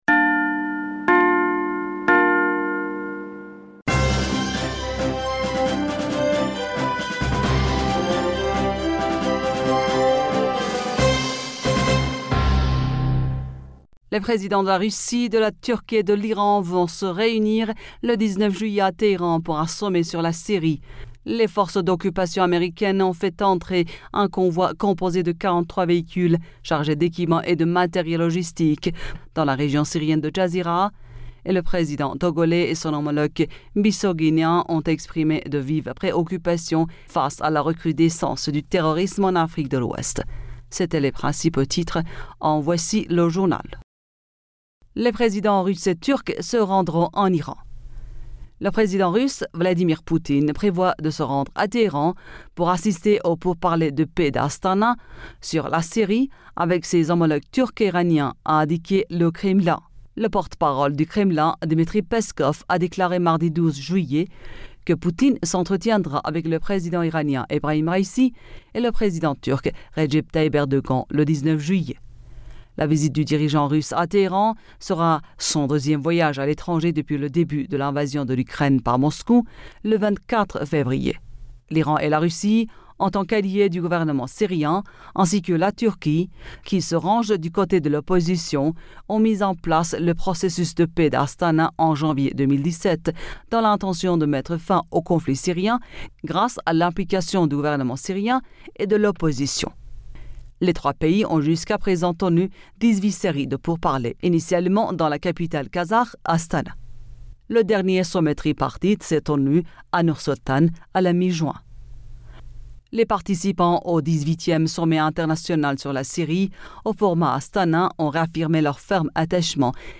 Bulletin d'information Du 13 Julliet